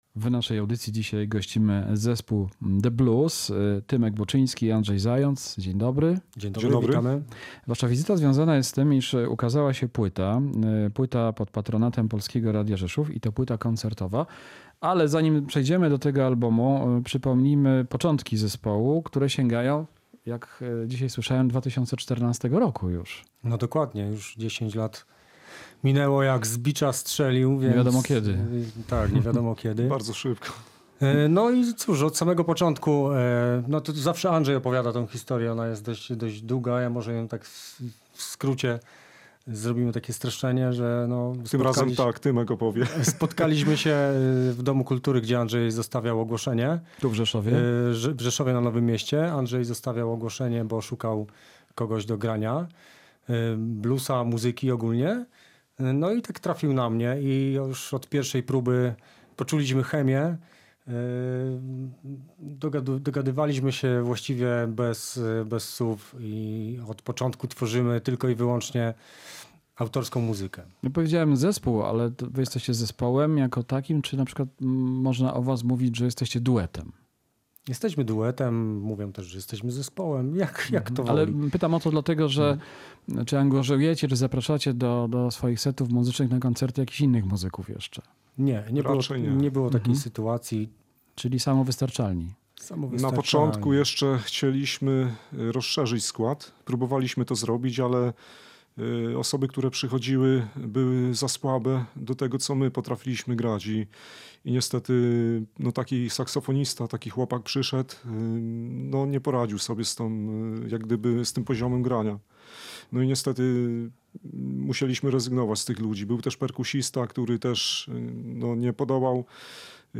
W audycji „W dobrym tonie” muzycy opowiadali o swoich początkach i doświadczeniach związanych z koncertami w nietypowych miejscach, jak Zakład Karny w Rzeszowie. https